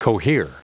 Prononciation du mot cohere en anglais (fichier audio)
Prononciation du mot : cohere